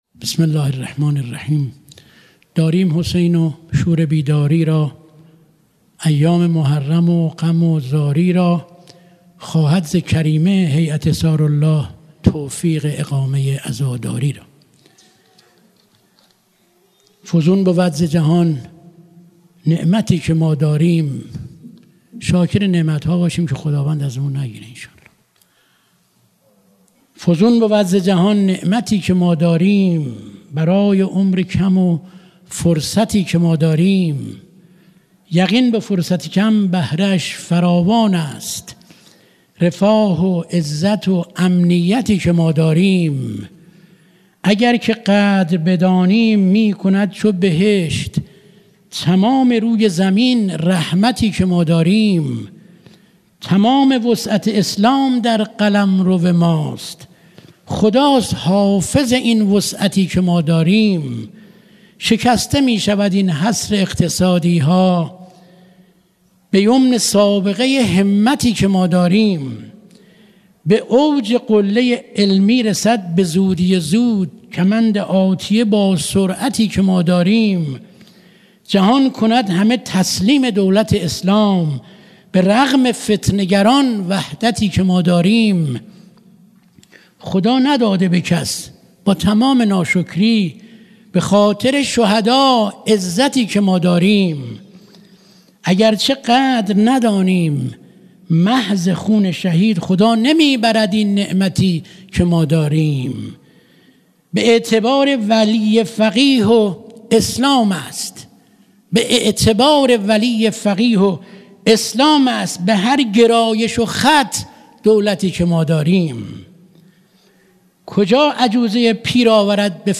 مراســم عـــزادارى شــب چهارم محرم
مراسم شب چهارم دهه اول محرم
شعر خوانی